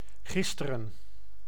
Ääntäminen
Vaihtoehtoiset kirjoitusmuodot (rikkinäinen englanti) yestaday Synonyymit the last day Ääntäminen US : IPA : [ˈjes.tə.deɪ] UK RP : IPA : /ˈjɛstədeɪ/ IPA : /ˈjɛstədɪ/ GenAm: IPA : /ˈjɛstɚdeɪ/ IPA : /ˈjɛstɚdɪ/